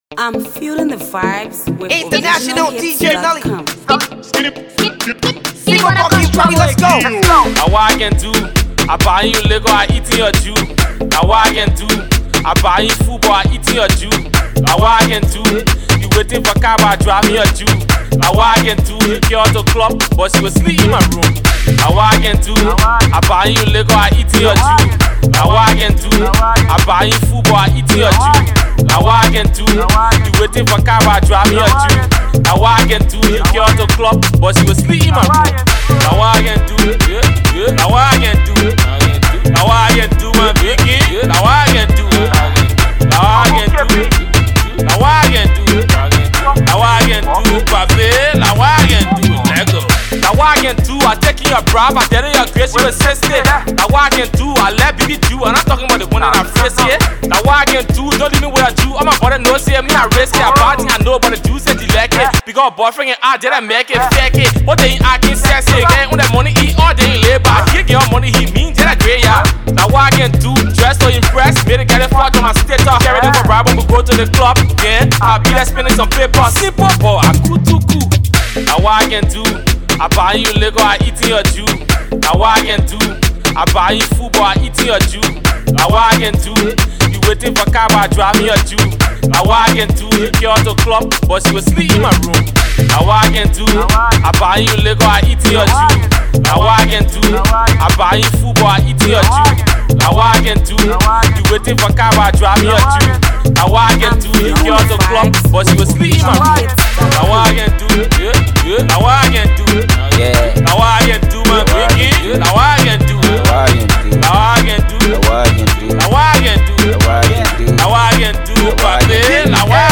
A new brand banger
Afro Pop Hipco Music